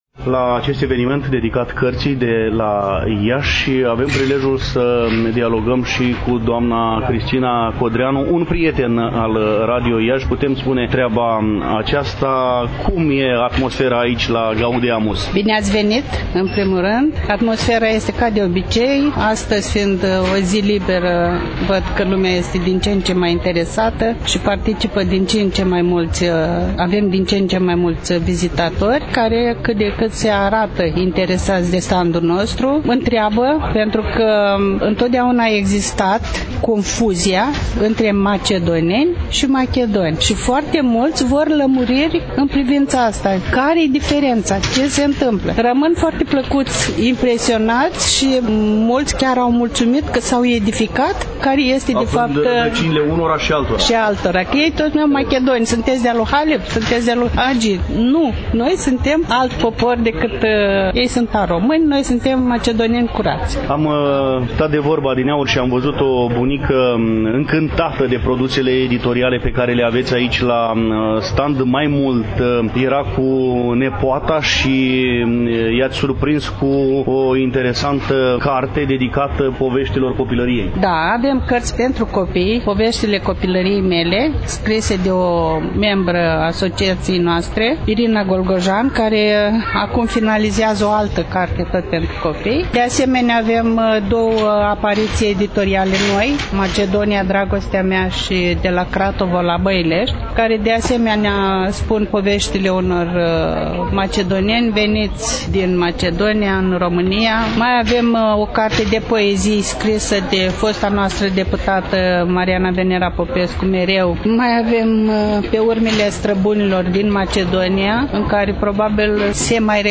Relatăm, astăzi, de la standul Asociației Macedonenilor din România